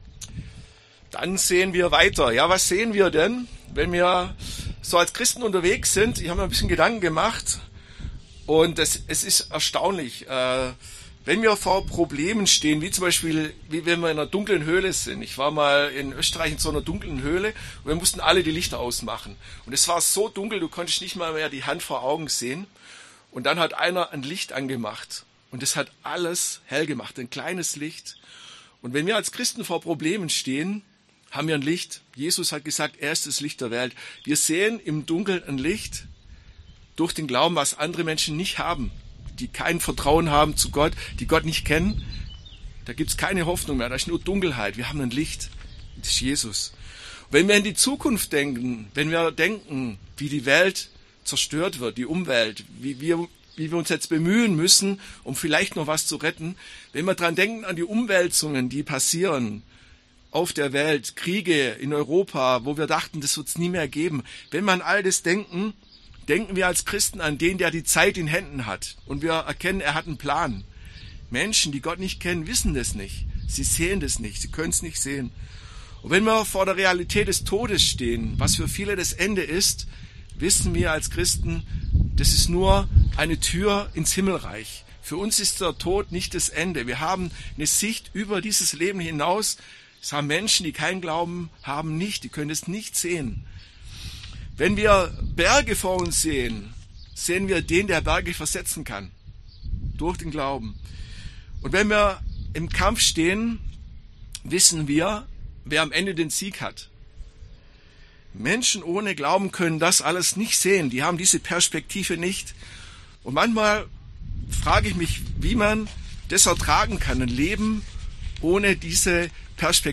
Predigt
im Gottesdienst an Himmelfahrt auf der Wiese.